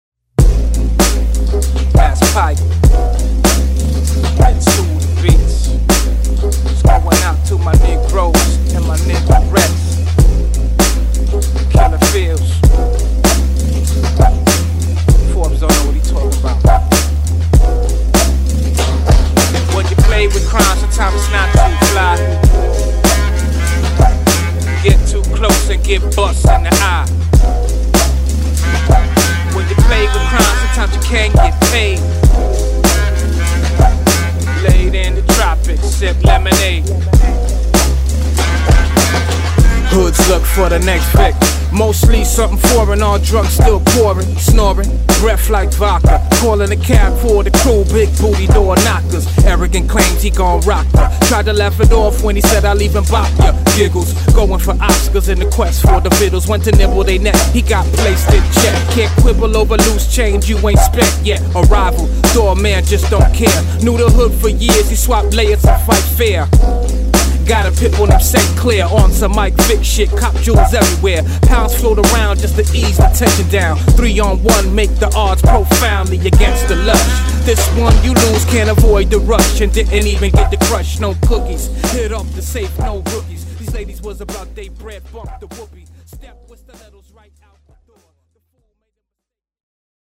90s アンダーグラウンドフレーヴァーを感じさせる一曲